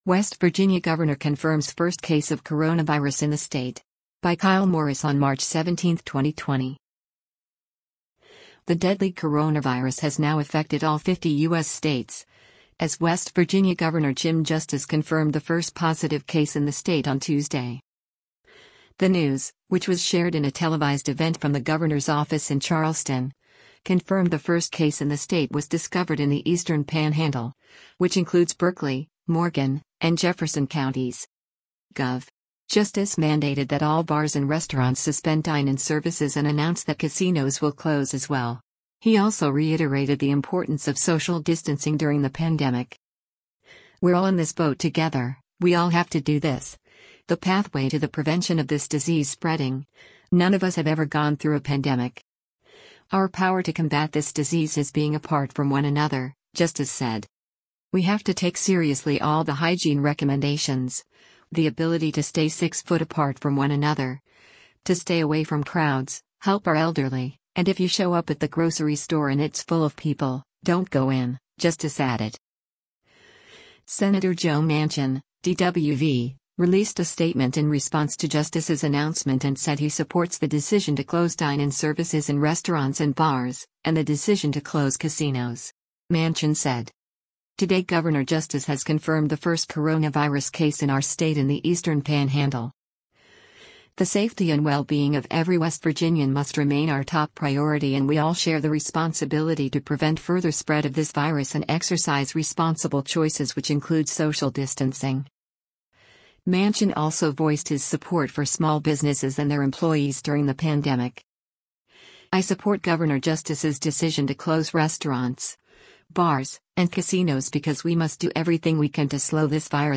The news, which was shared in a televised event from the governor’s office in Charleston, confirmed the first case in the state was discovered in the eastern panhandle, which includes Berkeley, Morgan, and Jefferson Counties.